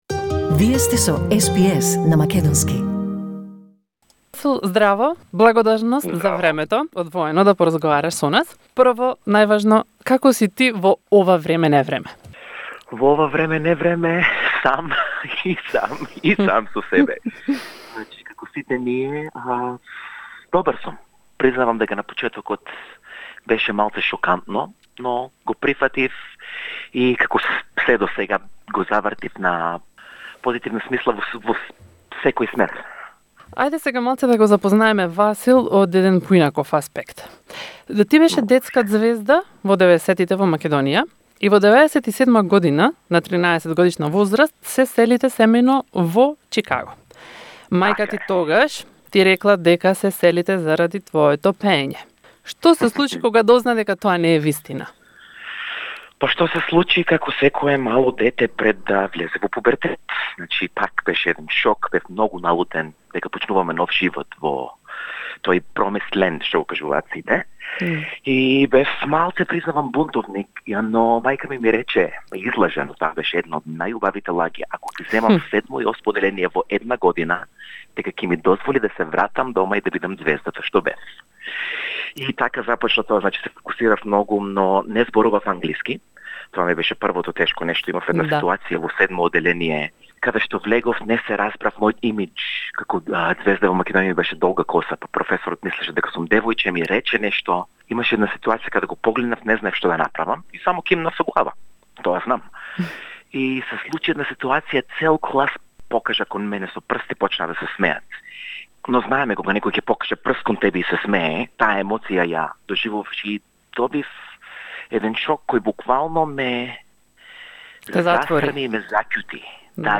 По тој повод SBS разговара со македонскиот преставник Васил Гарванлиев, кој на 35 годишна возраст има богата музичка кариера но и животно искуство.